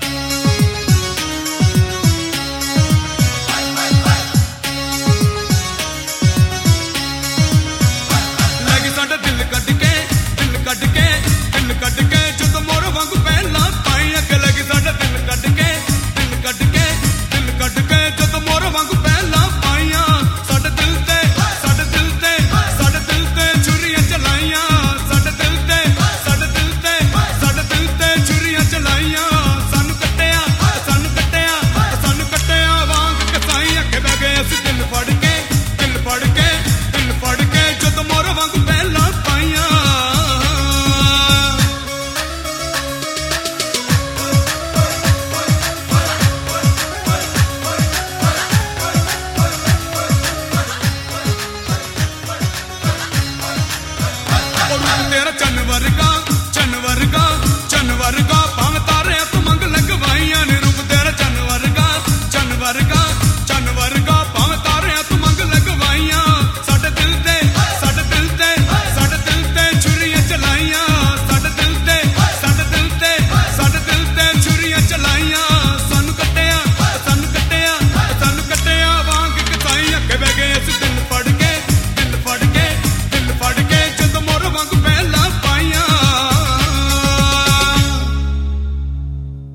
BPM208
Audio QualityPerfect (High Quality)
a fast and upbeat song